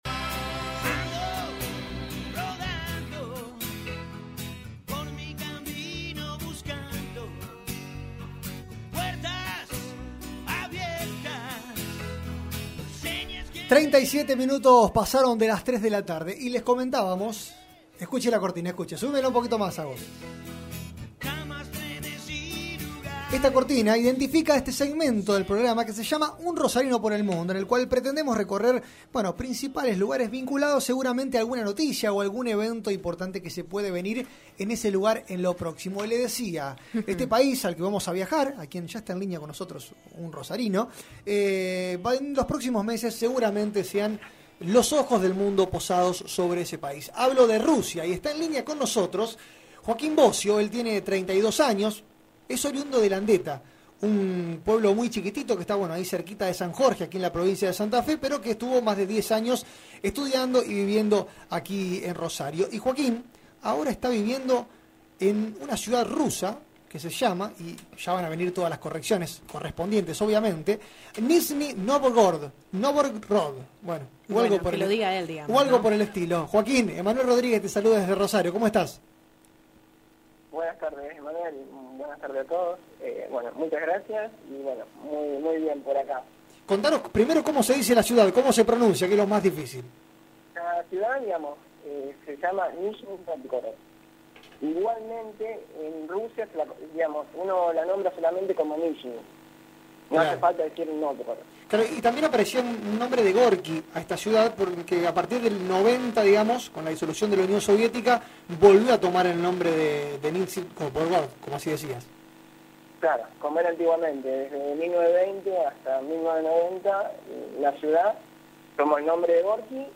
habló con el equipo de El Puente de Radio Mitre